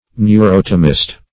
Neurotomist \Neu*rot"o*mist\
neurotomist.mp3